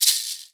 maraca2.wav